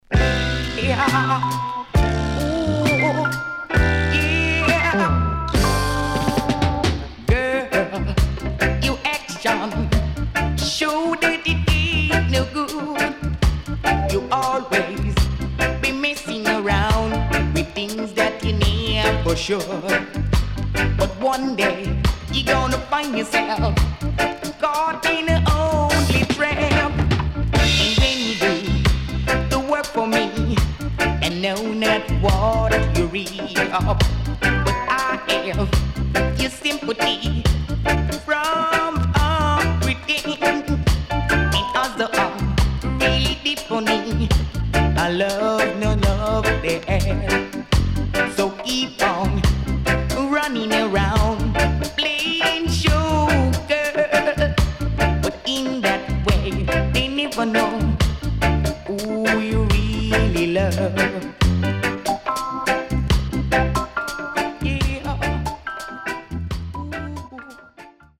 CONDITION SIDE A:VG(OK)〜VG+
W-Side Good Vocal
SIDE A:うすいこまかい傷ありますがノイズあまり目立ちません。